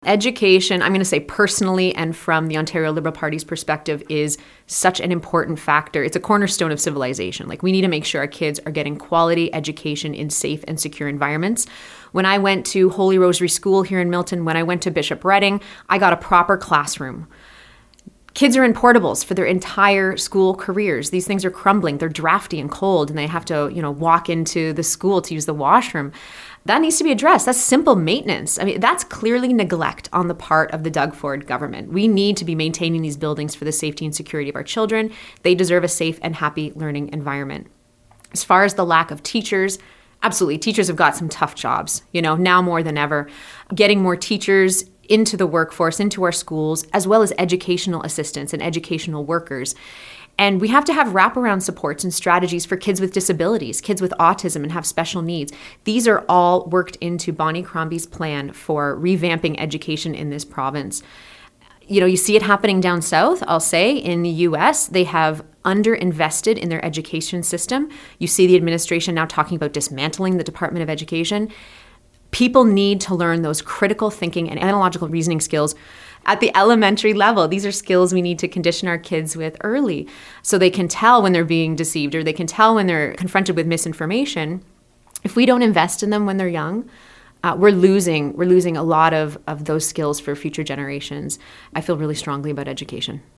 She chose to join us in-person at our Milton studios.
Here’s our interview: